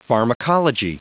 Prononciation du mot pharmacology en anglais (fichier audio)
Prononciation du mot : pharmacology